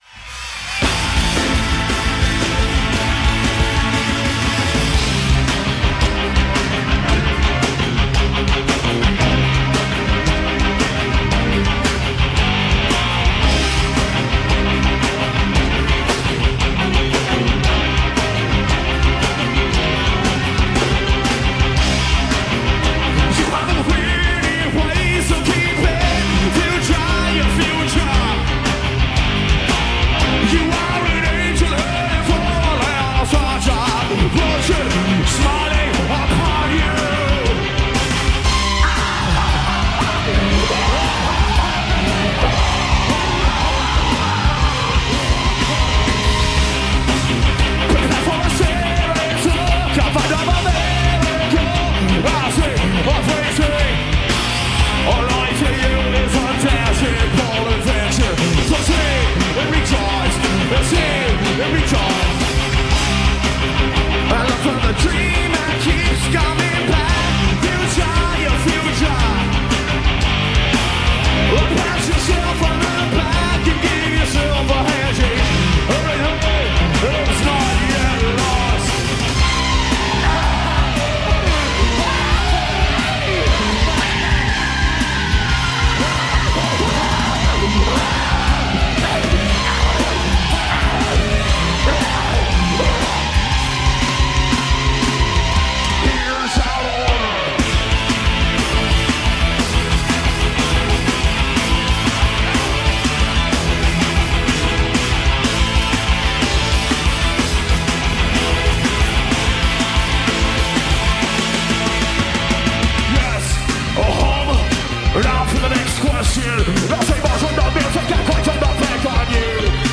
Blistering music from the Bay Area for a Thursday night.
is an American rock band from San Francisco